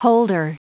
Prononciation du mot holder en anglais (fichier audio)
Prononciation du mot : holder